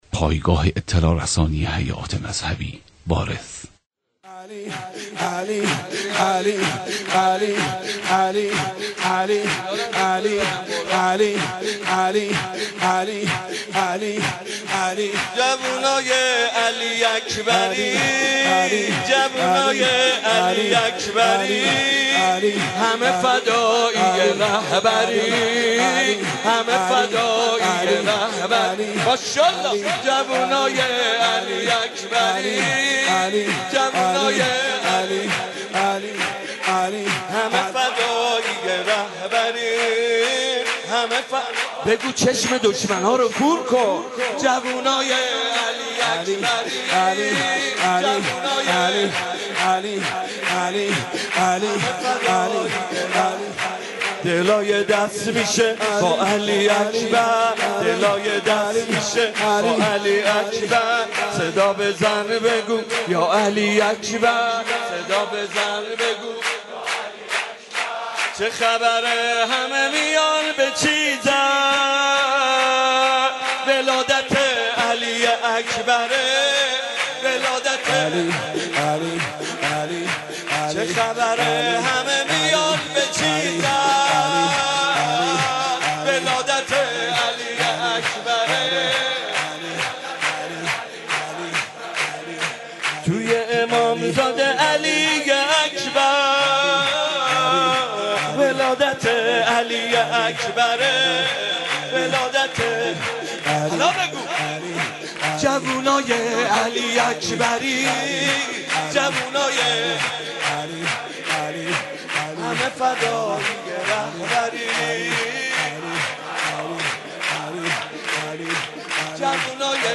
مولودی حاج محمد رضا طاهری به مناسبت میلاد با سعادت حضرت علی اکبر (ع)
هیئت رایة العباس ع